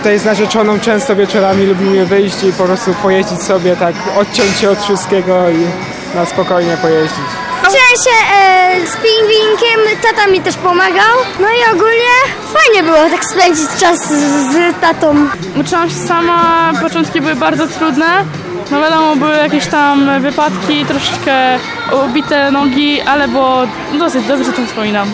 27sonda-lodowisko.mp3